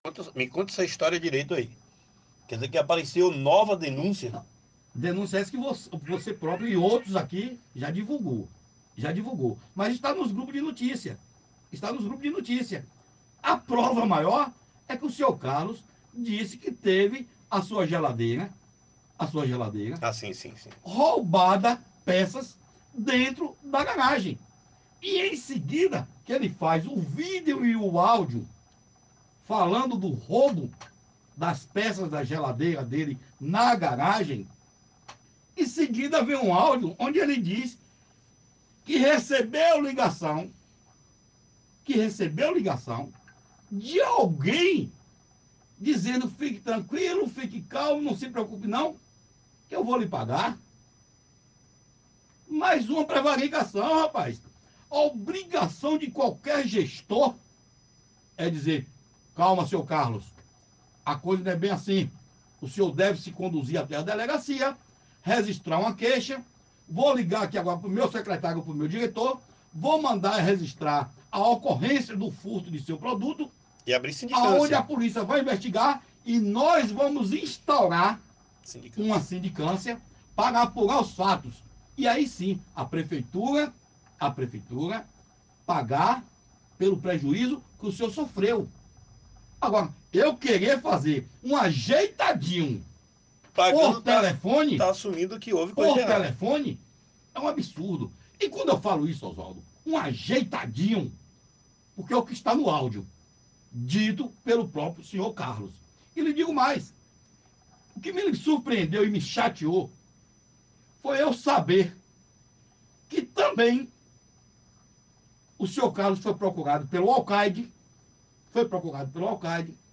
entrevista no Programa Rota da Informação na Rádio Rota News Web